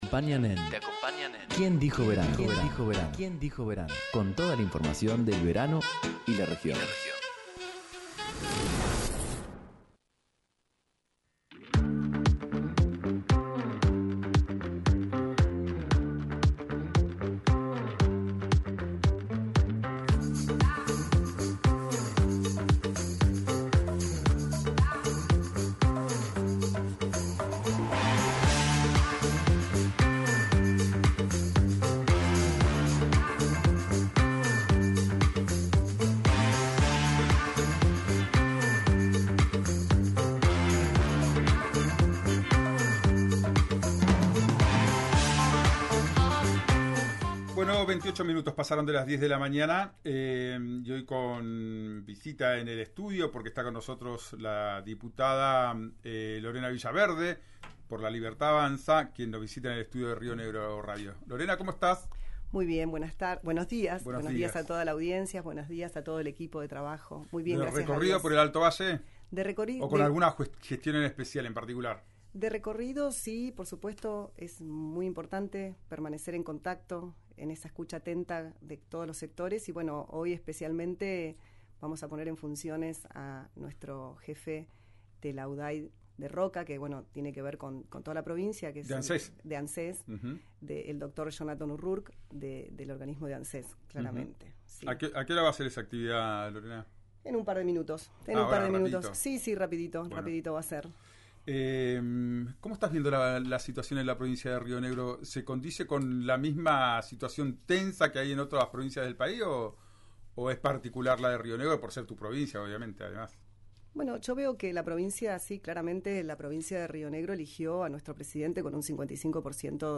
Escuchá a la ministra de Educación, Soledad Martínez, por RÍO NEGRO RADIO: